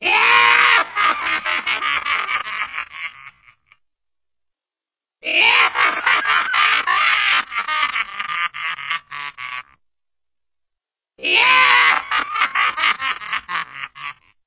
Evil Guy Laughing Sound Effect
Category 🗣 Voices
Evil Laugh Scream Stupid sound effect free sound royalty free Voices